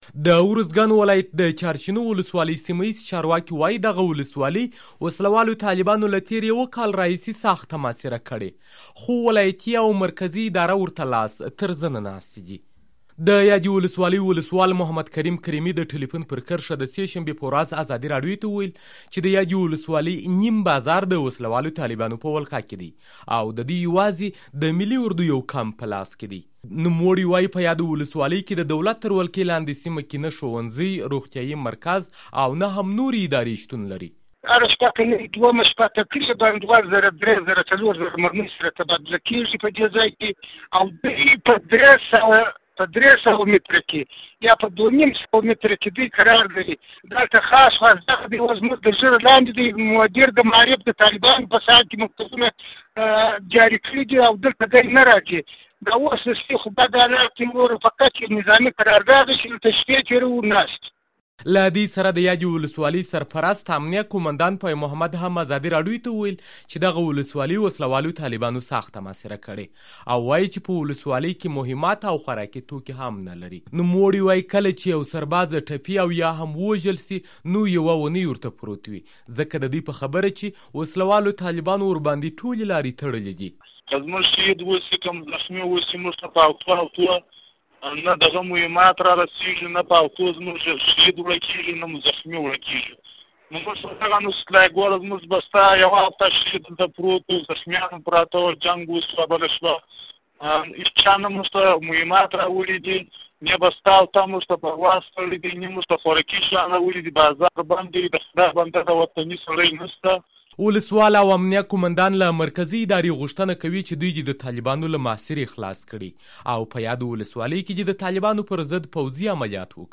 د یادې ولسوالۍ ولسوال محمد کریم کریمي د ټیلیفون پر کرښه د سې شنبې په ورځ ازادي راډيو ته وویل چې د یادې ولسوالۍ نیم بازار د وسلهوالو طالبانو په ولکه کې ده او د دوی یوازې یو د ملي اردو کمپ په لاس کې دي.